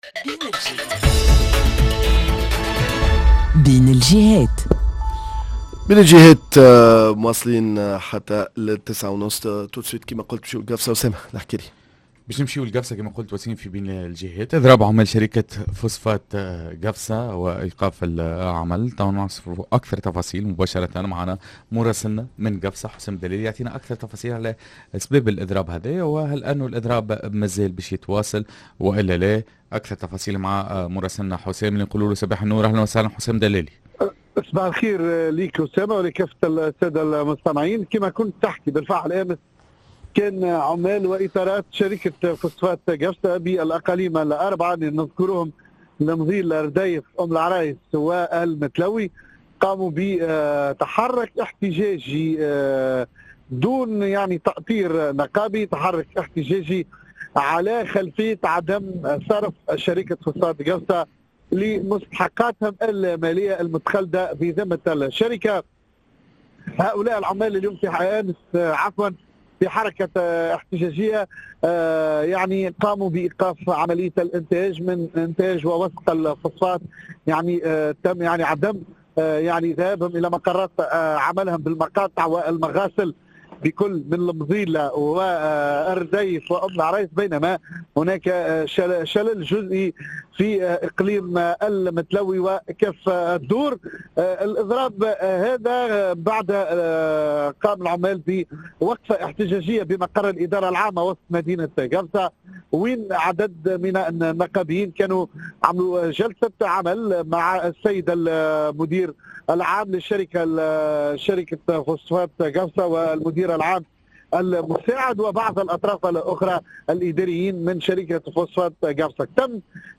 بين الجهات: إضراب عمال شركة فسفاط قفصة: مطالب ملحة وتوقف جزئي في الإنتاج مراسلنا